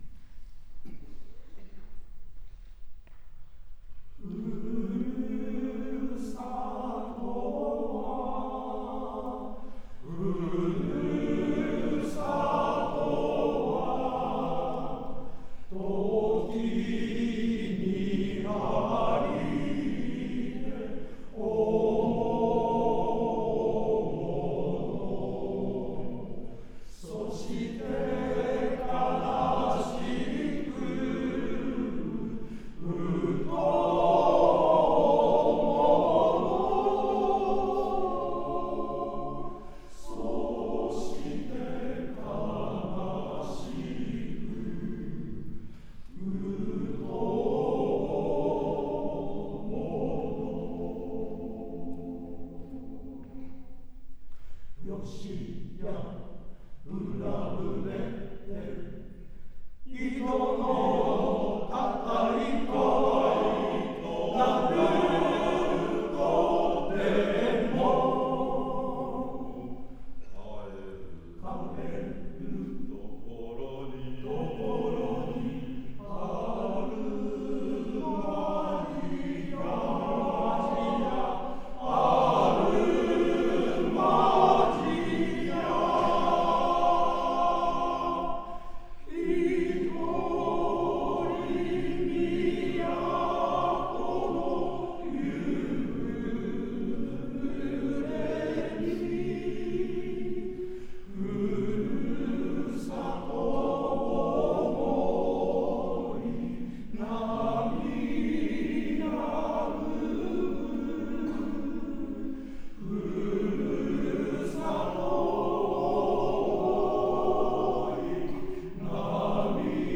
コーラスフェスティバル
会場 世田谷区民会館